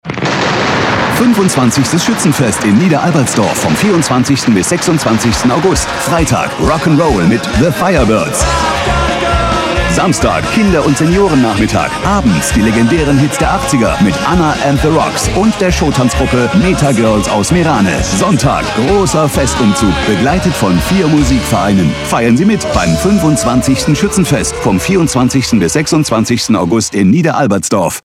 Radiospot zum 25. Niederalbertsdorfer Schützenfest